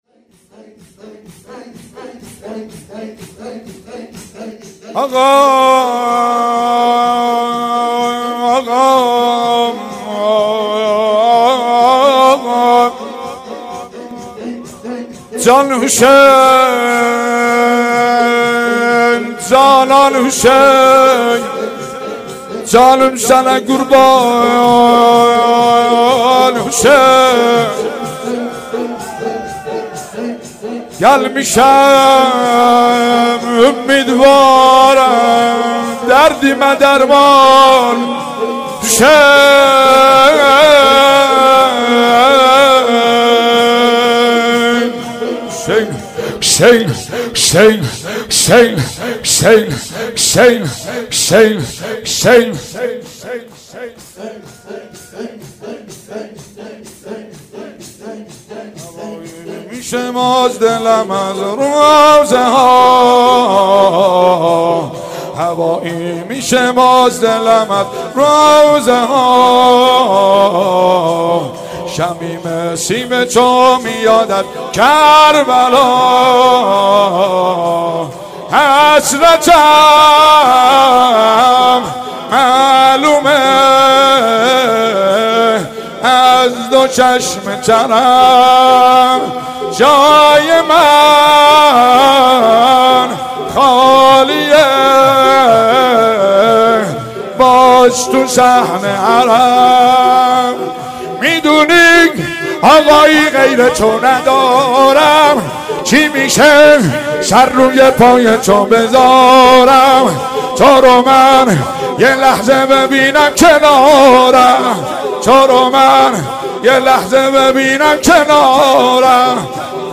مداحی آذری
شور، زمزمه